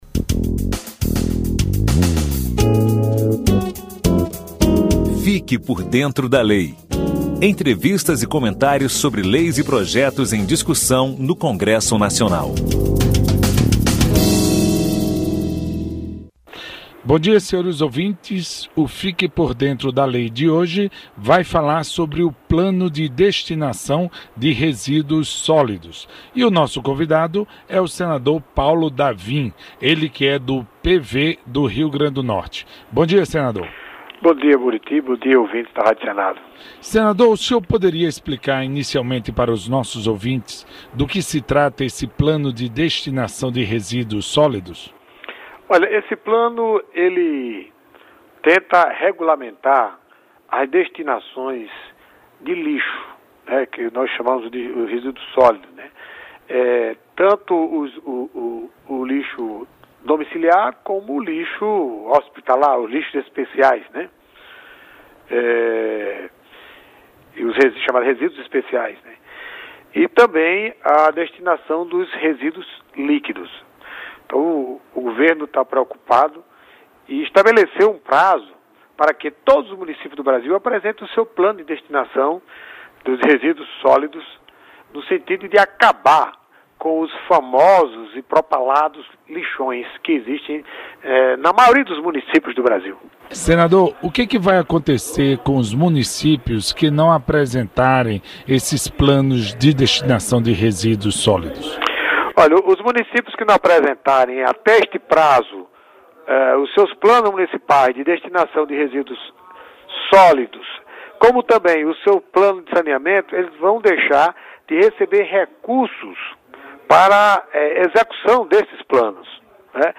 Bate papo sobre leis e direitos do cidadão
Programa veiculado na Rádio Senado entre junho de 2010 e dezembro de 2013.